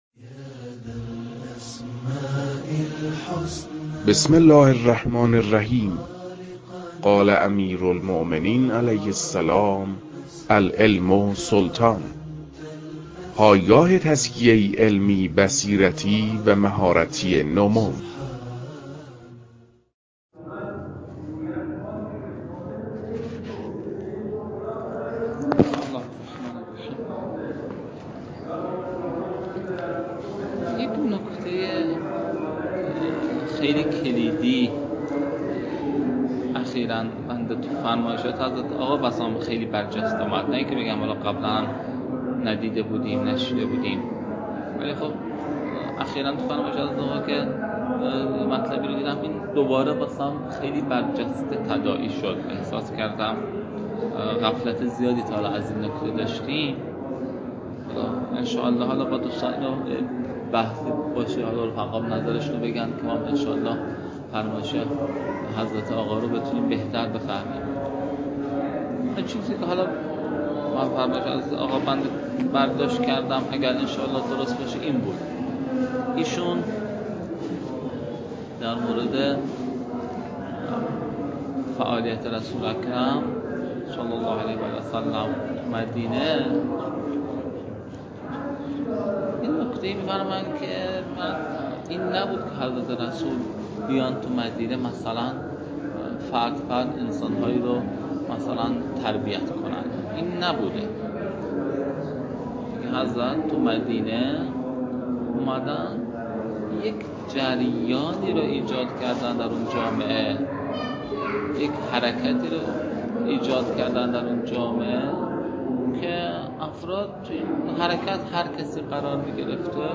محتوای این فایل طرح موضوع پژوهش است در محور ضرورت کشف ملاک در احراز حصول نصاب لازم برای ارتقای سطوح کمی تشکیلات های حکومتی اسلامی که در جمع برخی از طلاب شهرستان قزوین ازائه گشته است.